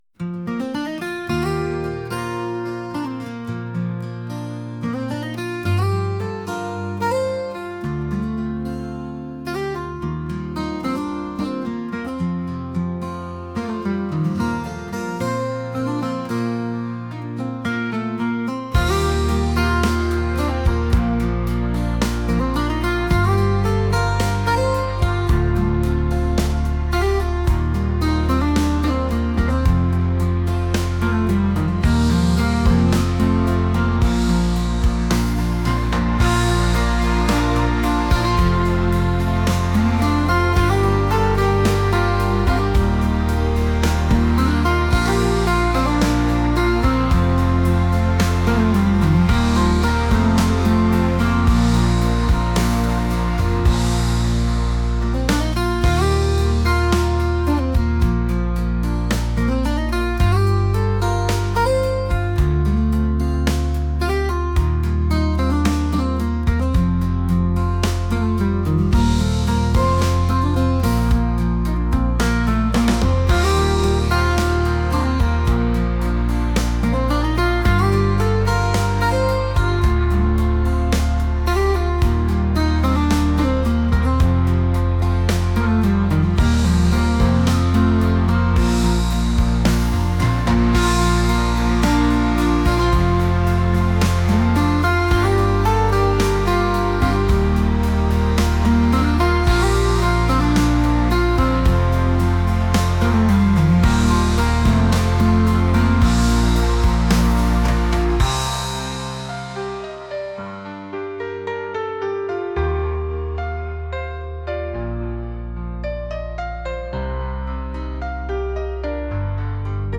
acoustic | pop | rock